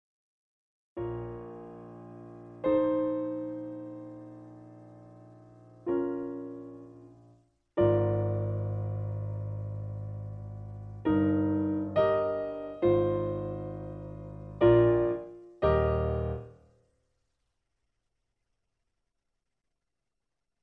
Semitone lower. Piano Accompaniment